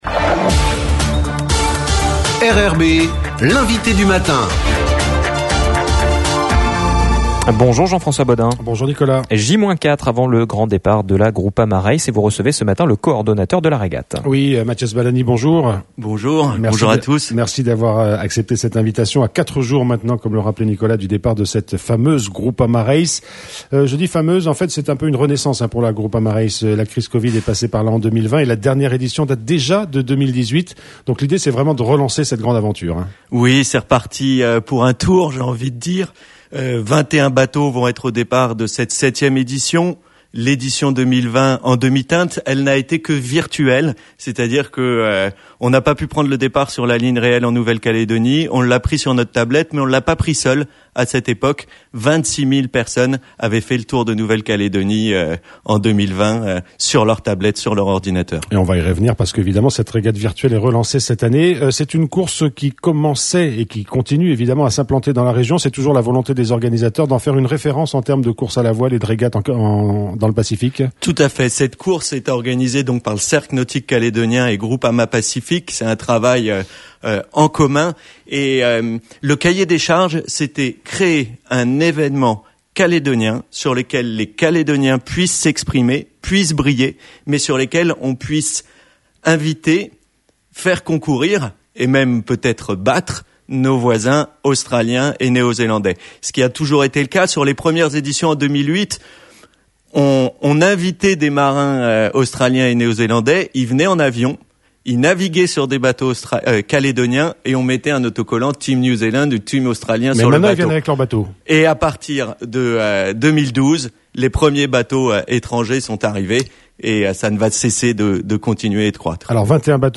INVITE DU MATIN : 15/06/22